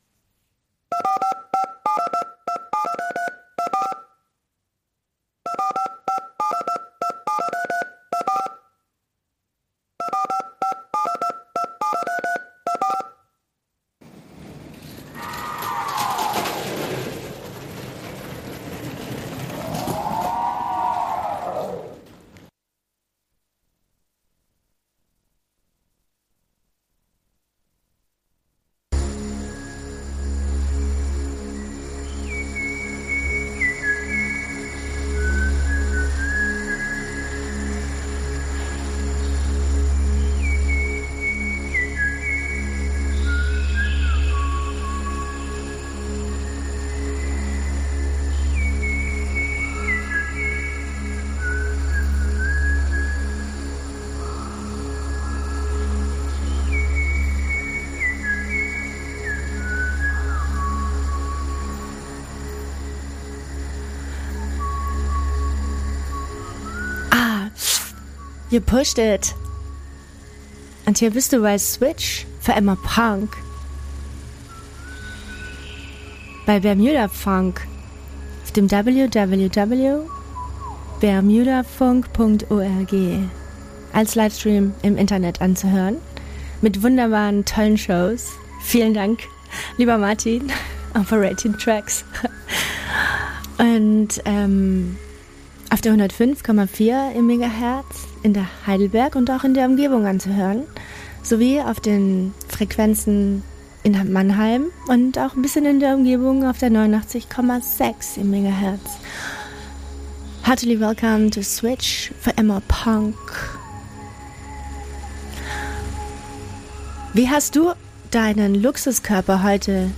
Der Punkcast ist ein Live-Mitschnitt & die Weiterentwicklung von "Switch - Für immer Punk".